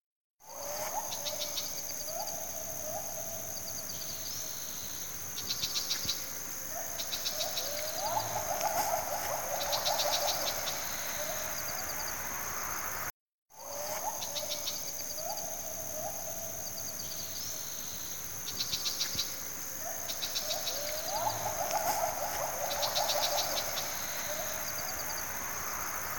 Alcaudón Pardo (Lanius cristatus)
Nombre en inglés: Brown Shrike
Localidad o área protegida: Parque Nacional Khao Yai
Condición: Silvestre
YAO-YAI-con-monos.mp3